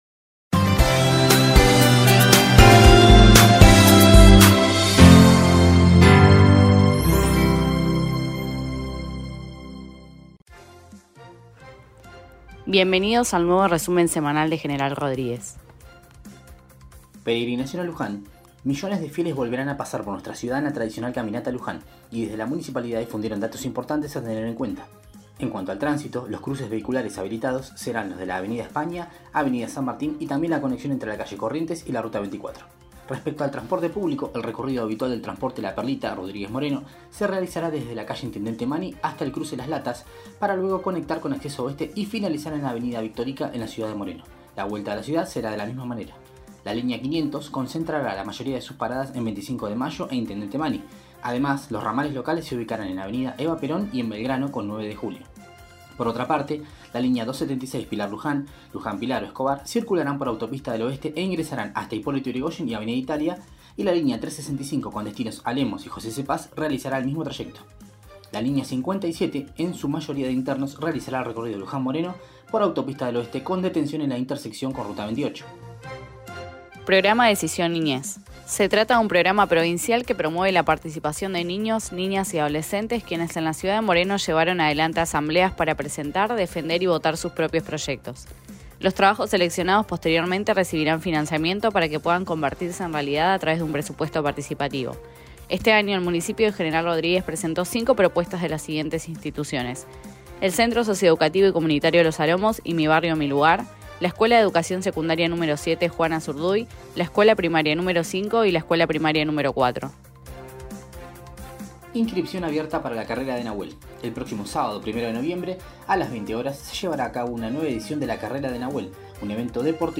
resumen de noticias semanales